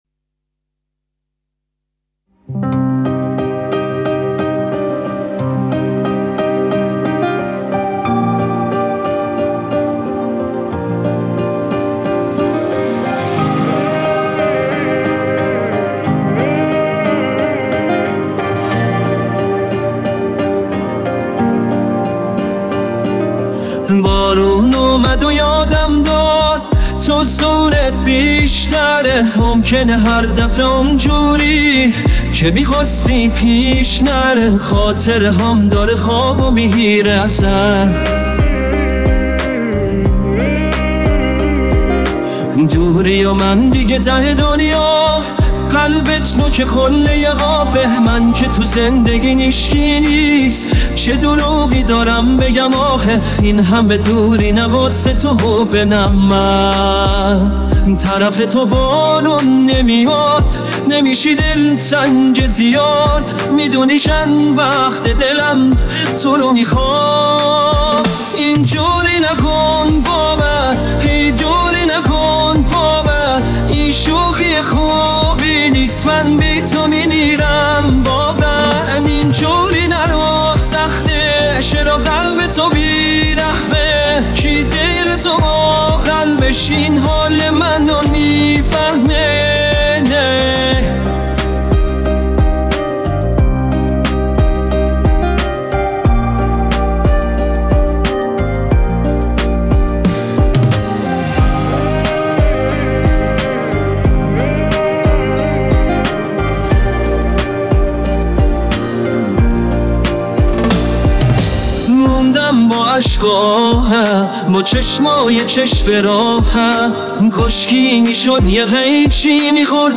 اهنگ های هوش مصنوعی